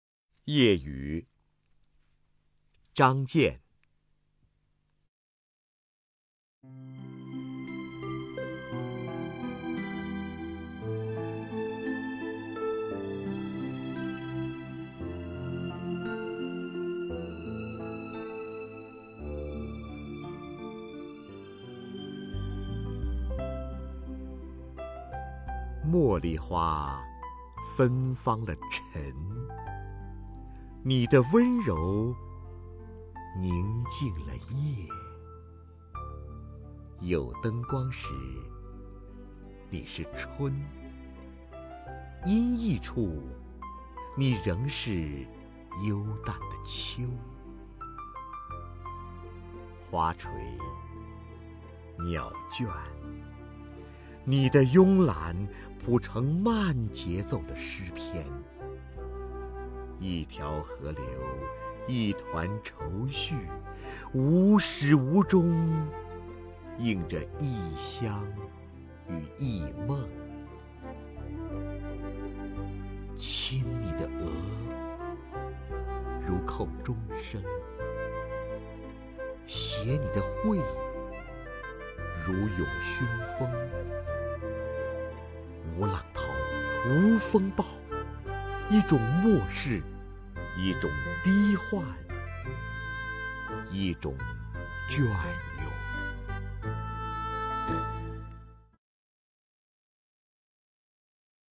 首页 视听 名家朗诵欣赏 瞿弦和
瞿弦和朗诵：《夜语》(张健)